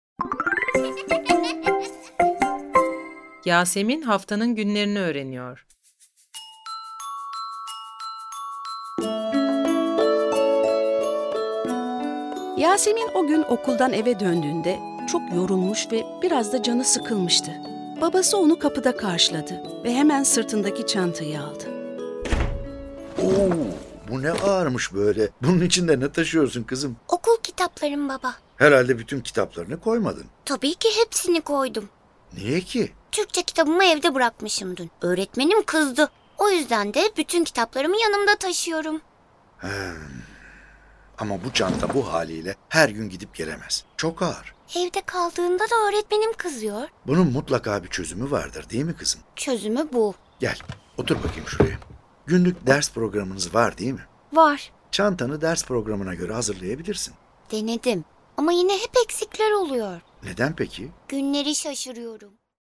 Yasemin Haftanın Günlerini Öğreniyor Tiyatrosu